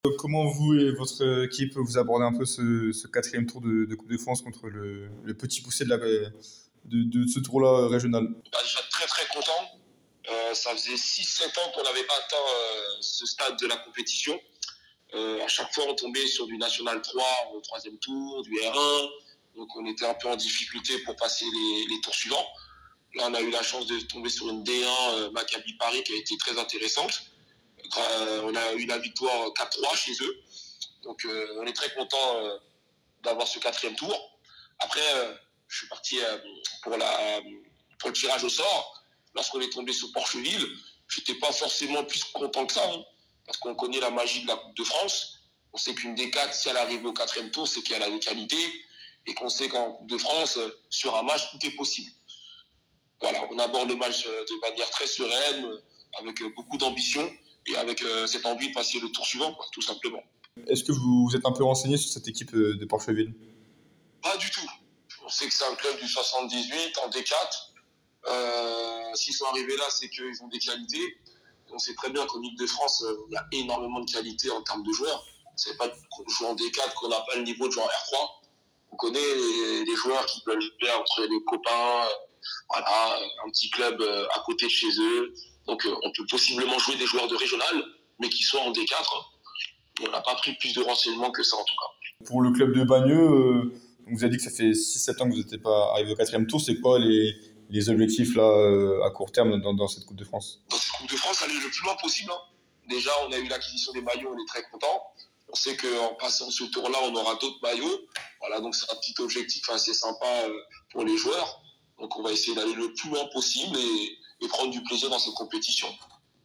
L’interview